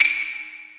drum-hitwhistle.ogg